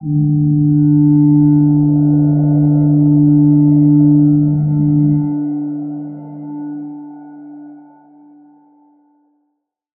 G_Crystal-D4-mf.wav